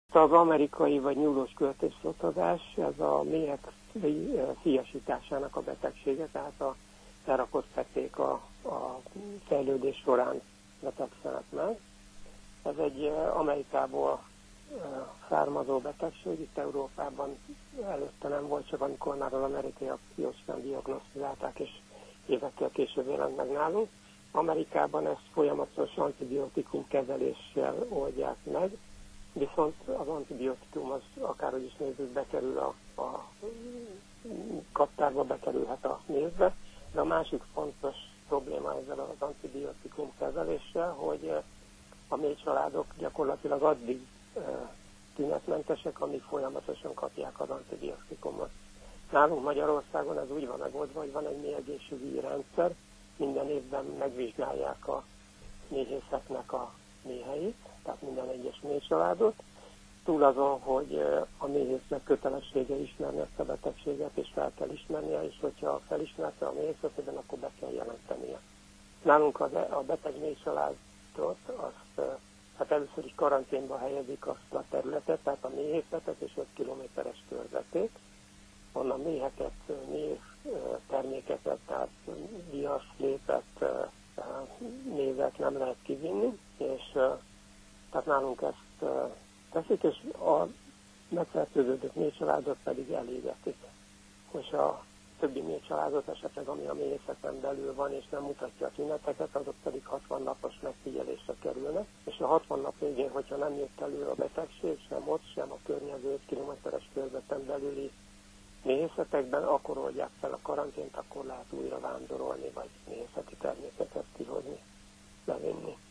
méhész beszélt a betegségről, és hogy milyen intézkedéseket szükséges ilyenkor megtenni.